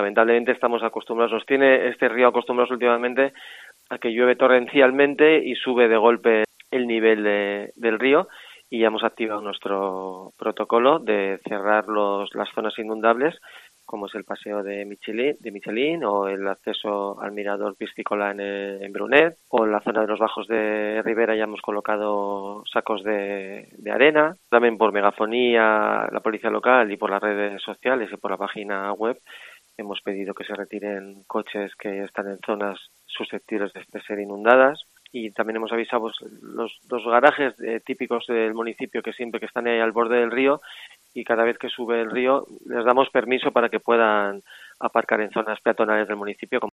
Agustín Valdivia, alcalde de Lasarte